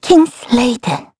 voices / heroes / kr
Xerah-Vox_Kingsraid_kr.wav